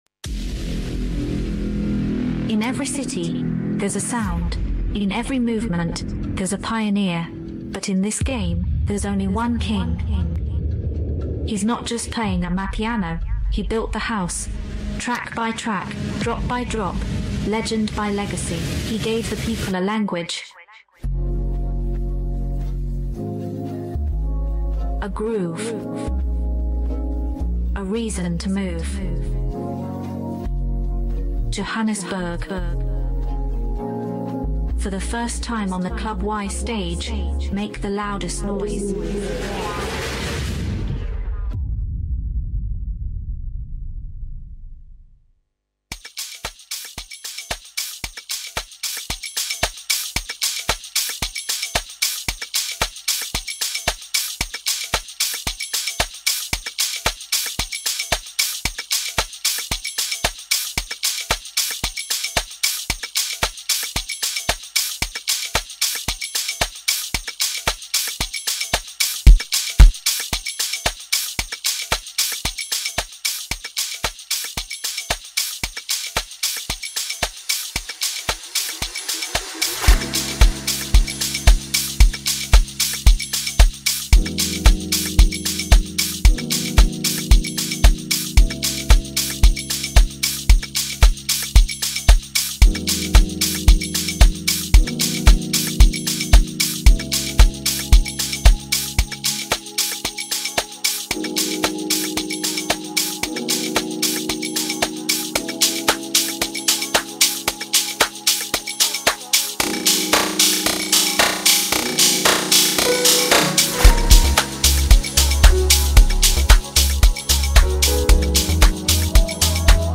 Amapiano faithful, prepare yourselves!
early listens suggest a masterful instrumental journey